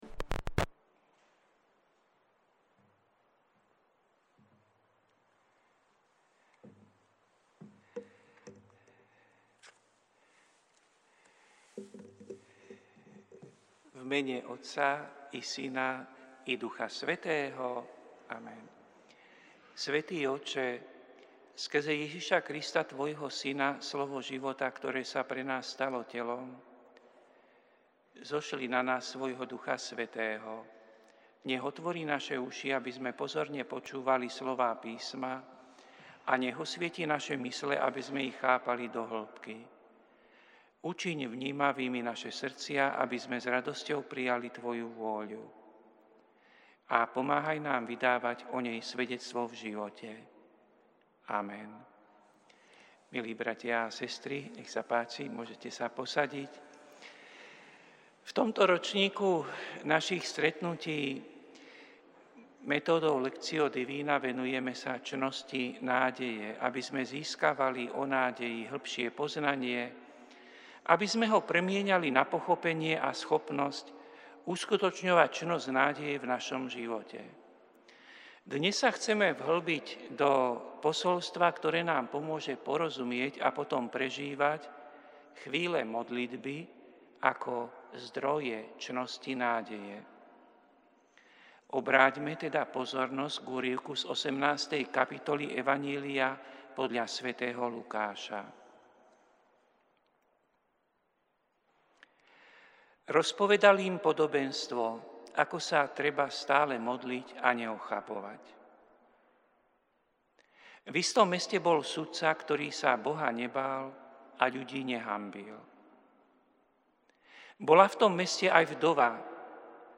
Prinášame plný text a audio záznam z Lectio divina, ktoré odznelo v Katedrále sv. Martina 5. marca 2025.